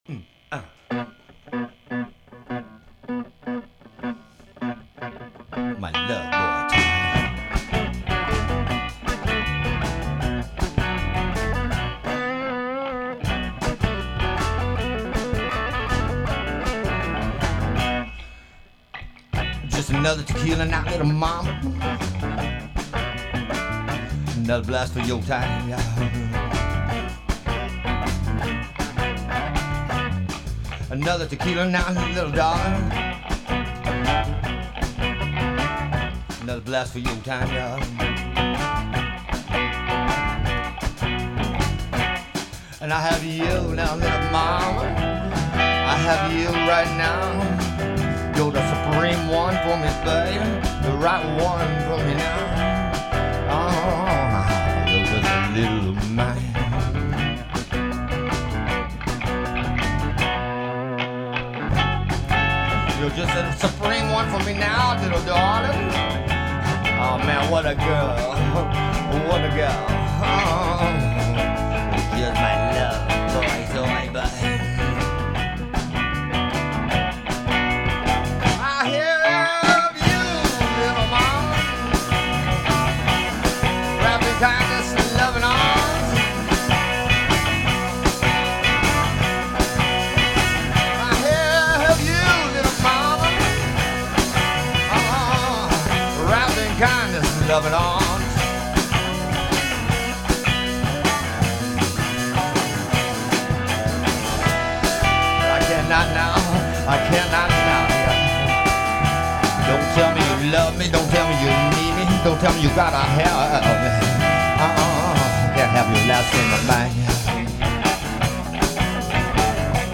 upbeat dance song
bass guitar
drums